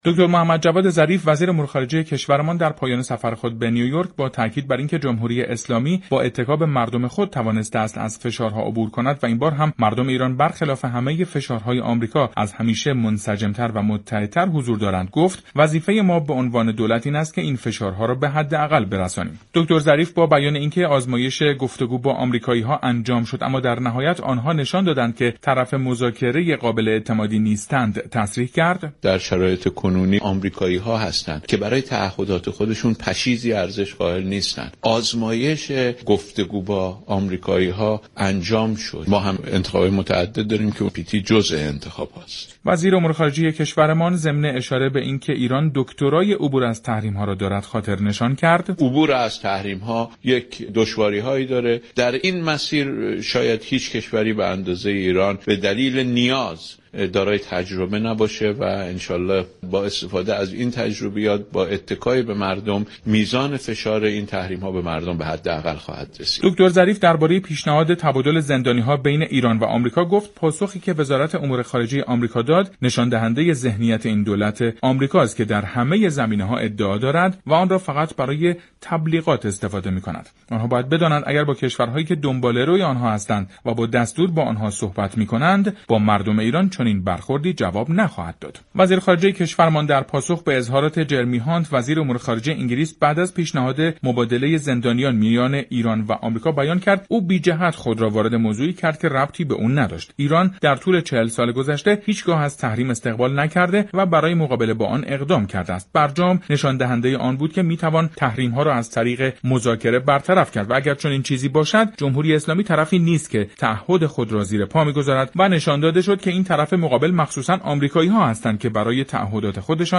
این كارشناس مسائل سیاسی تصریح كرد : یك سال از وعده های اروپا برای راه اندازی كانال مالی می گذرد و هنوز اقدام موثر و عملی دیده نشده است. برنامه جهان سیاست شنبه تا چهارشنبه ساعت 16:30 از رادیو ایران پخش می شود.